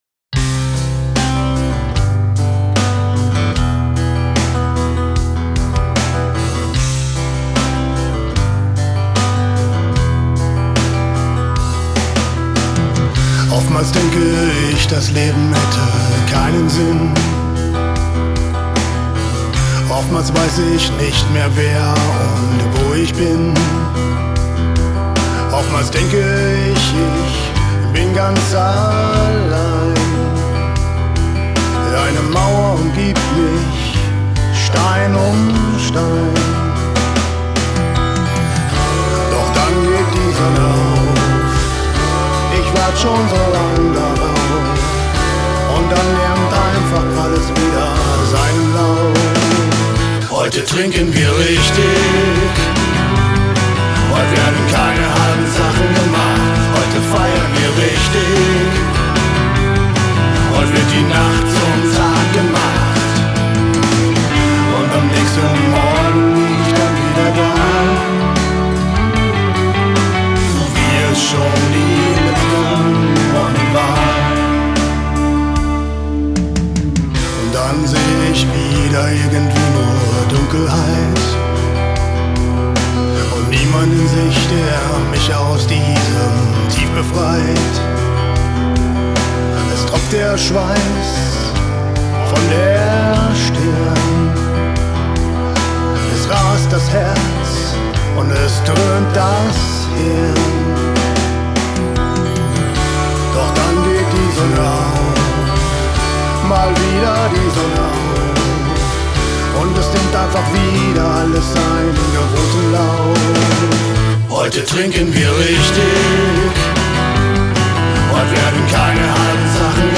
saubere und klare Klangkulisse